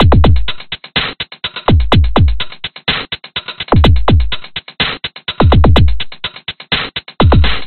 经典808drumloop
描述：lassic808drum loop
Tag: 120 bpm Dub Loops Drum Loops 172.31 KB wav Key : Unknown